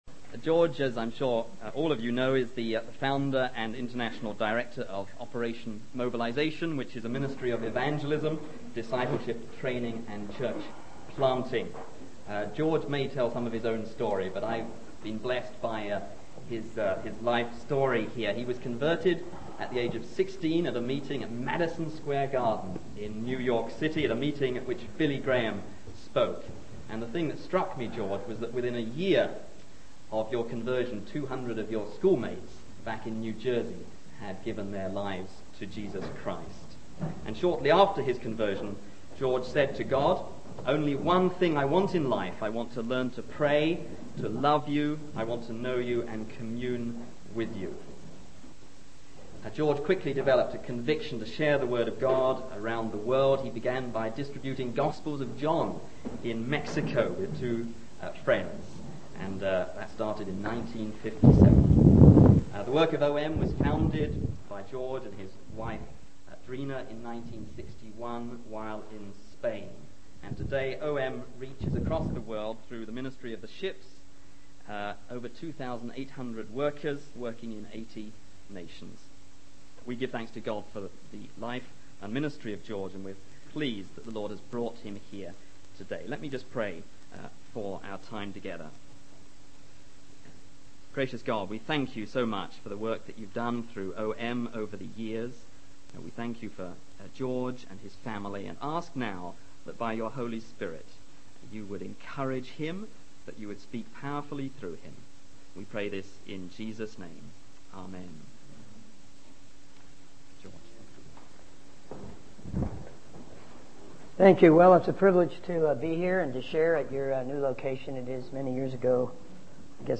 In this sermon, the speaker discusses the challenges of recruiting long-term career missionaries, particularly due to financial factors. He emphasizes the importance of studying the Book of Acts and highlights the significance of the local church in global missions. The speaker encourages the audience to support and appreciate their pastors, who face immense pressure and attacks from Satan.